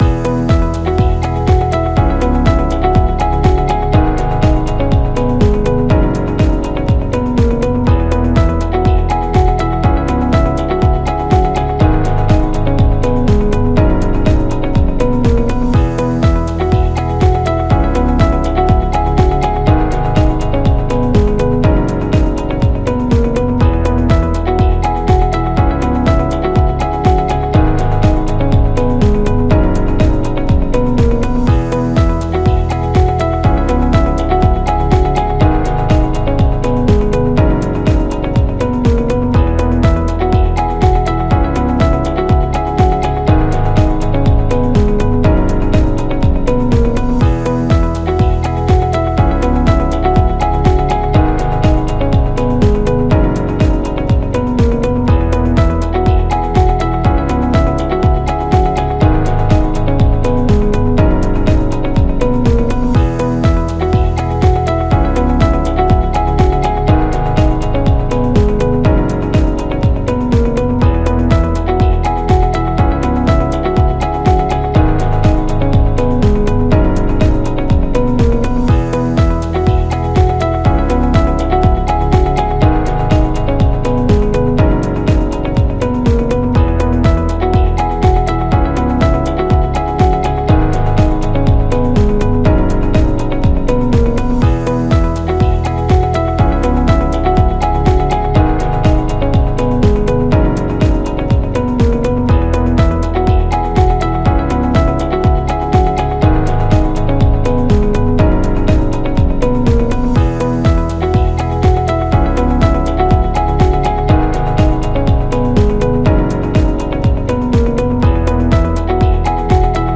Motivational Corporate Loop